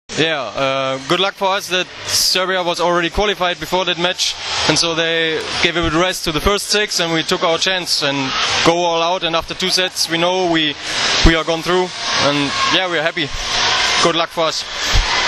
PREVOD IZJAVE